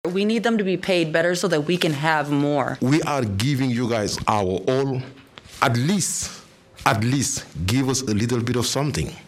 KALAMAZOO, MI (WKZO AM/FM) – Support staff for Kalamazoo Public Schools turned out in large numbers at the district’s Board of Education meeting Thursday night, demanding salary hikes and getting a positive response from the board.